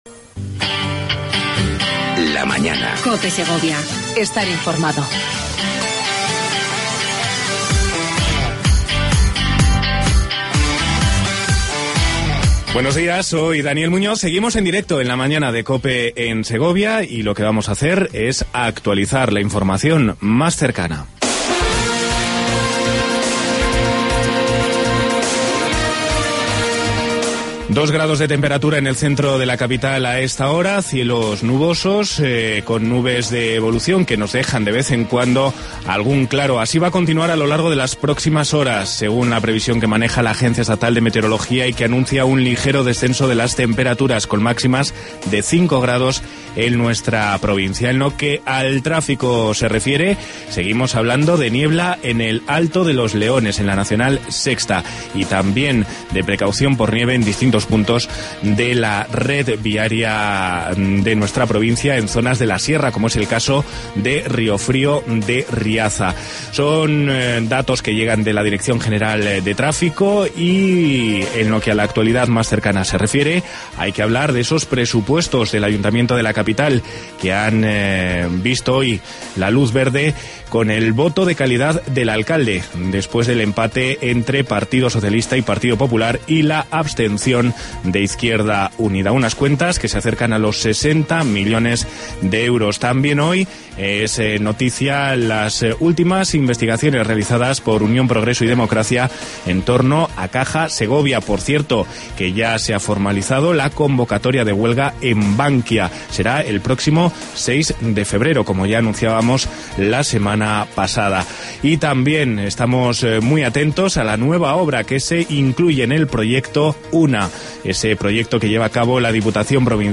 AUDIO: Entrevista Institucional con Javier López Escobar, Delegado Territorial de La Junta de Castilla y León en segovia.